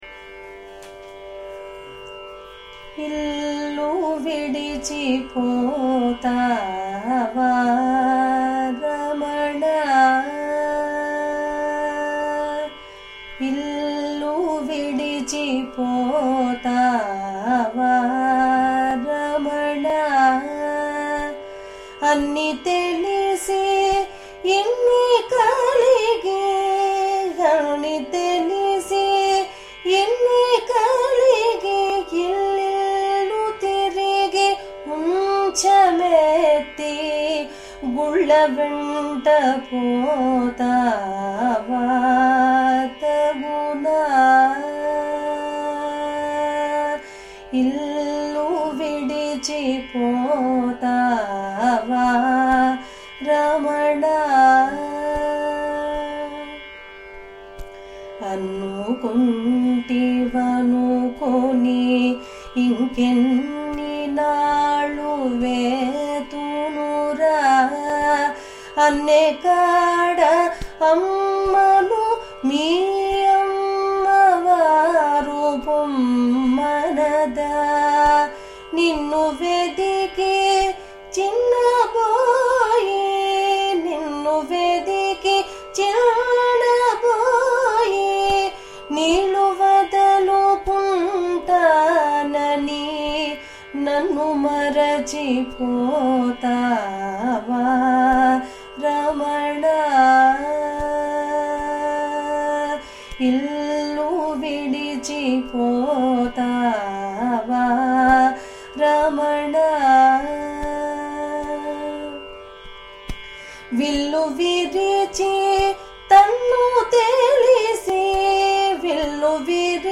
రాగం: చారుకేశి
తాళం: రూపక తాళం